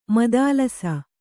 ♪ madālasa